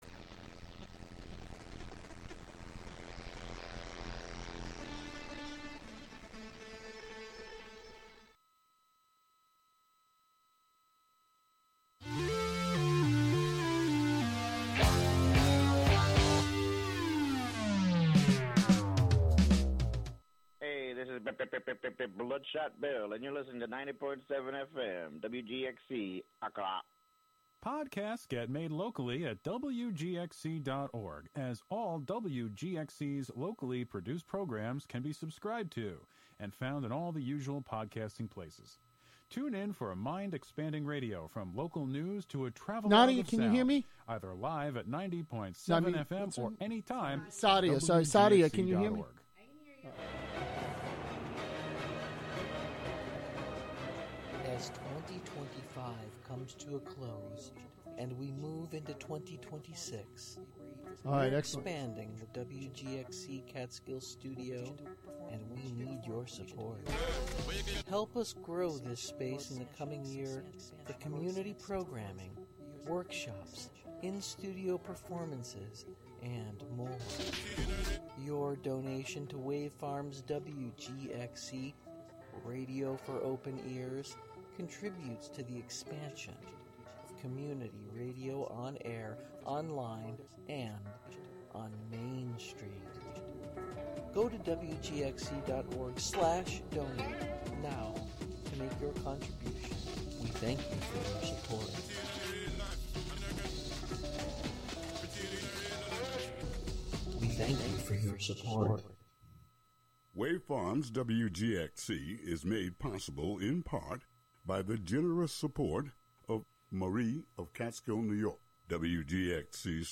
It's a timeless anthem of hope and transformation, ushering us into a conversation about releasing, returning, and rising.